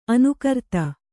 ♪ anukarta